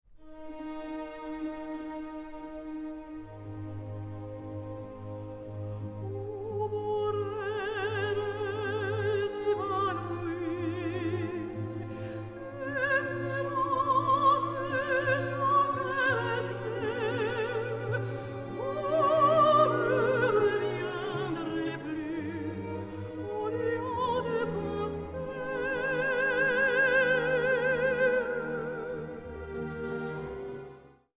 Rare French and Italian Opera Arias
Soprano
Released in stunning Super Audio CD surround sound.